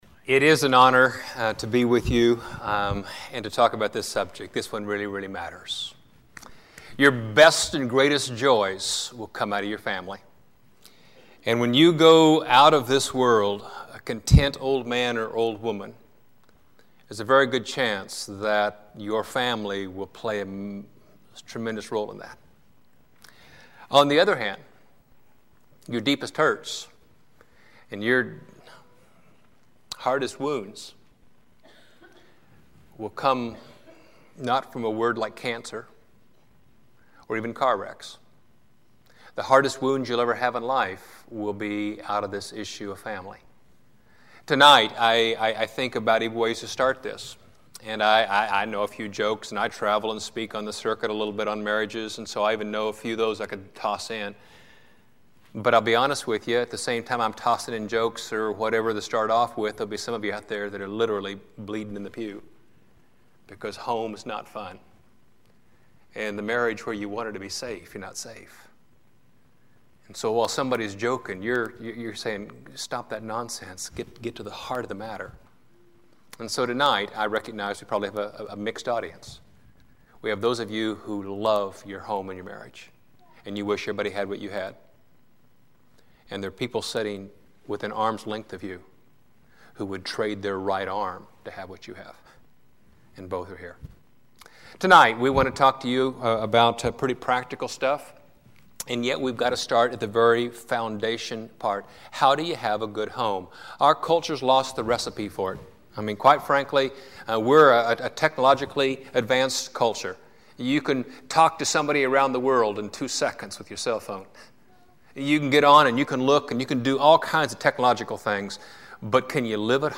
Still the One Taught at: Mt. Hope Church of Christ, Joplin, MO Still the One // February 7, 2007 Audio